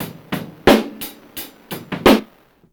Warm_Fuzzy 88bpm.wav